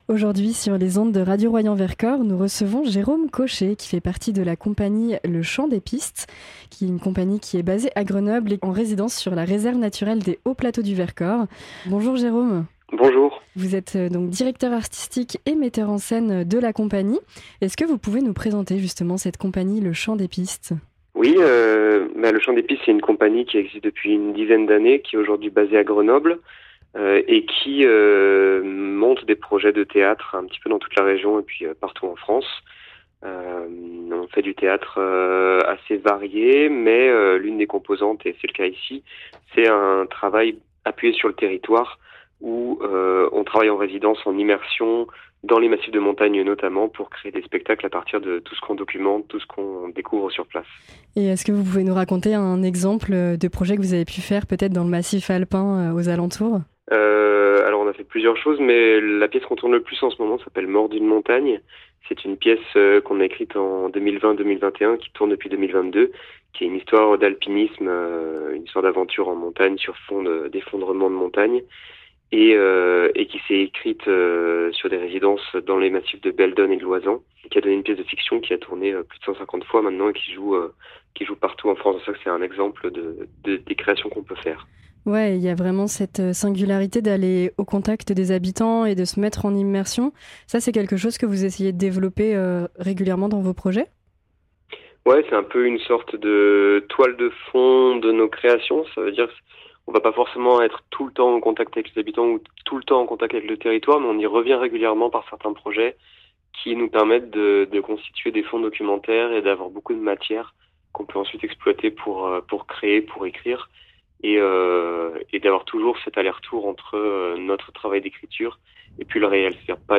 En résidence immersive sur le territoire, l’équipe a mené un travail de recherche mêlant observation, écoute et rencontres, pour créer une pièce sensible autour du lien entre l’humain et la nature. Interview